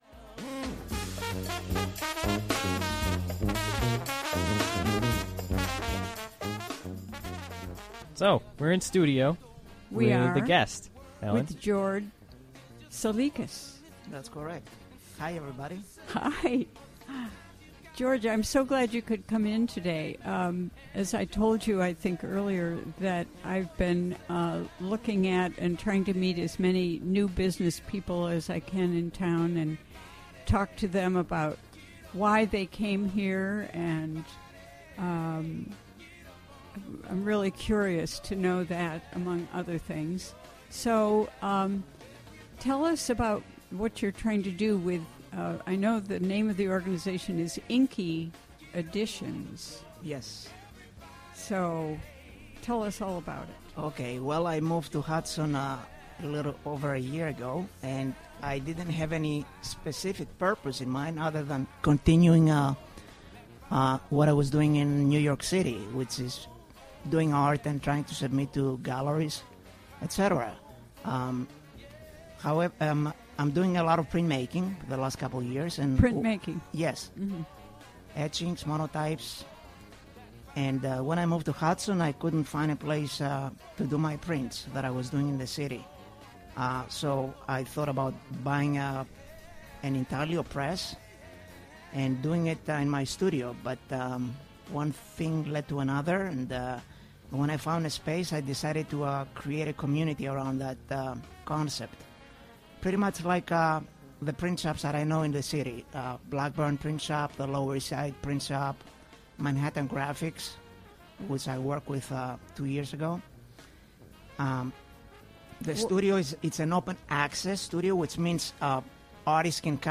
Interview was conducted during the WGXC Afternoon Show.